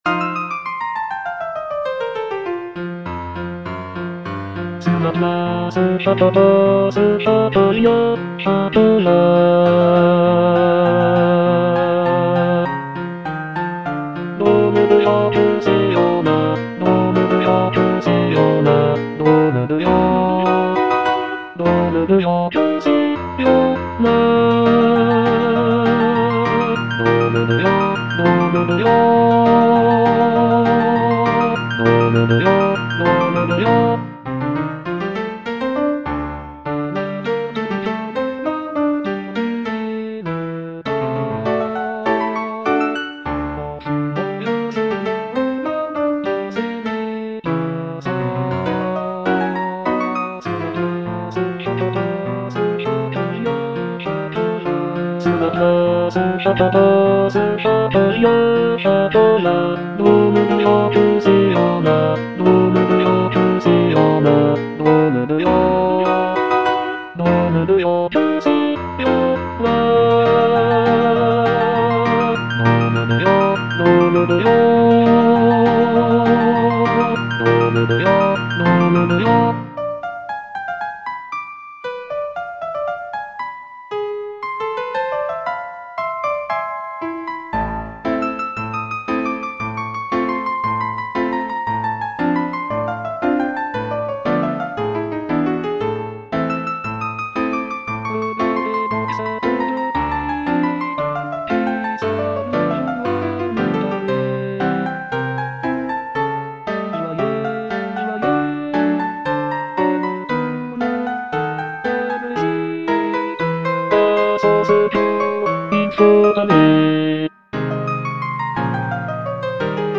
G. BIZET - CHOIRS FROM "CARMEN" Sur la place (bass II) (Emphasised voice and other voices) Ads stop: auto-stop